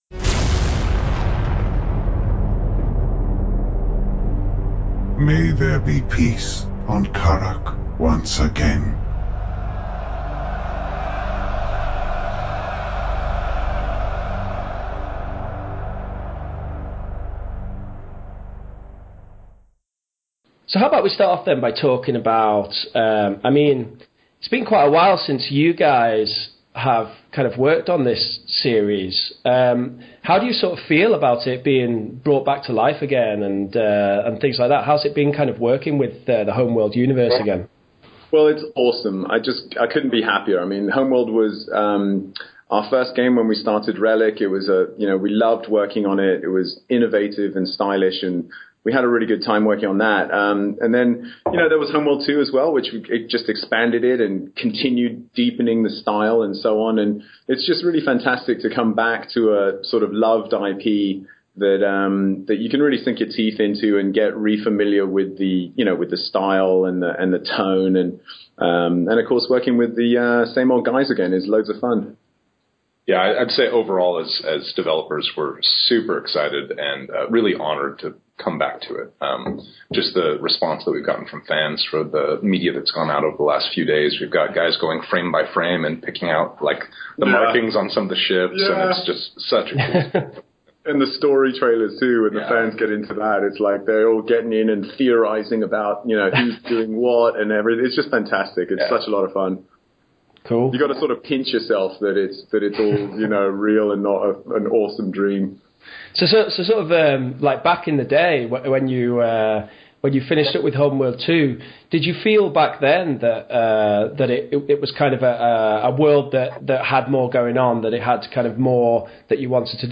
Homeworld: Deserts of Kharak interview with Blackbird Interactive